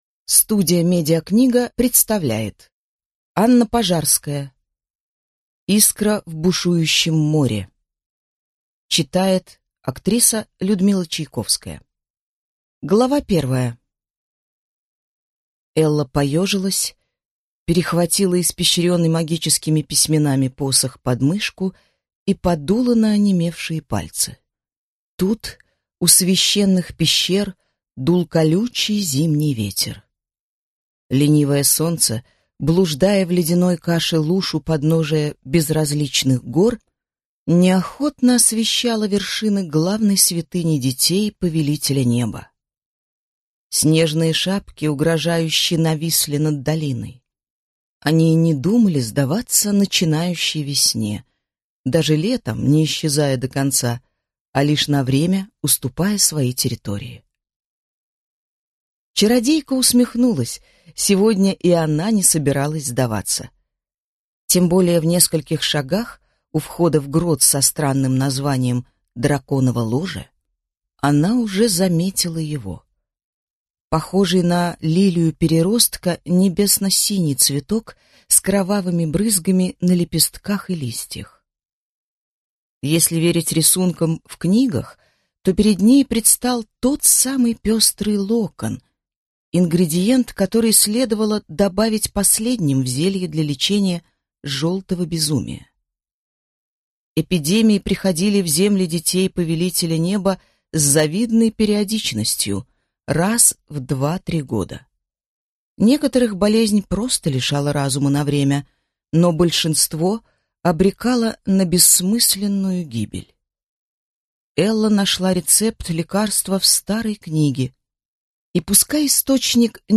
Аудиокнига Искра в бушующем море | Библиотека аудиокниг